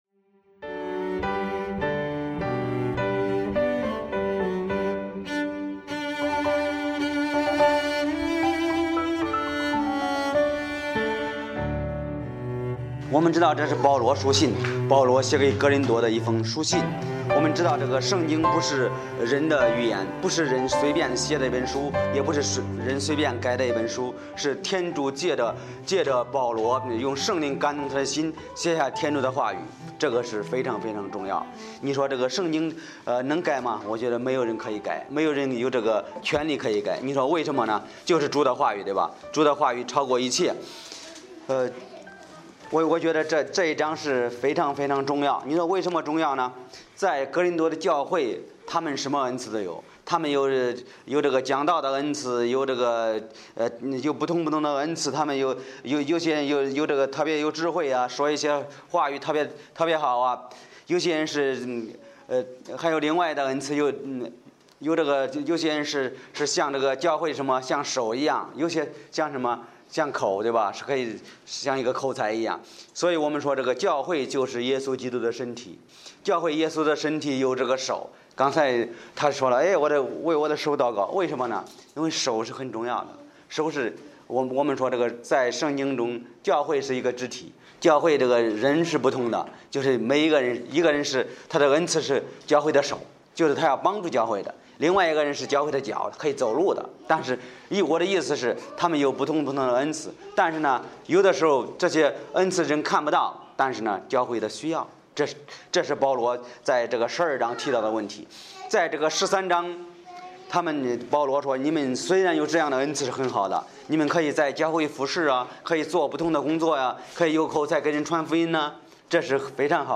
讲道者